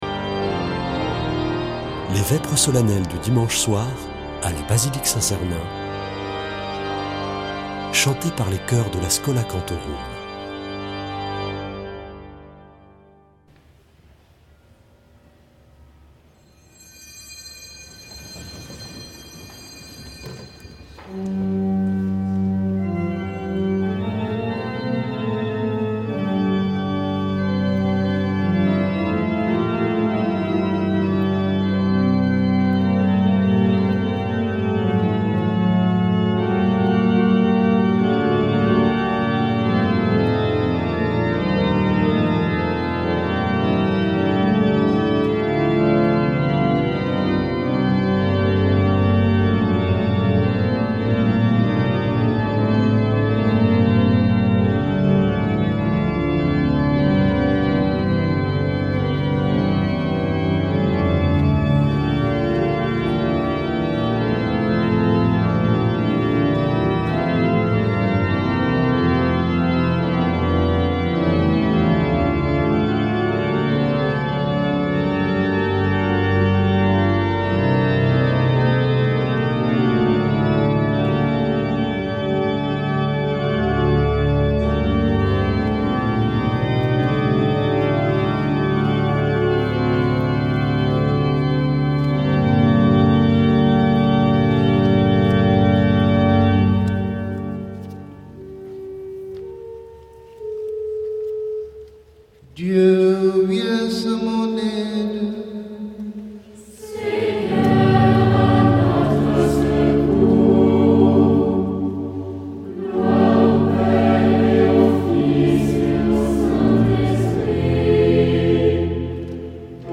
Vêpres de Saint Sernin du 28 janv.
Schola Saint Sernin Chanteurs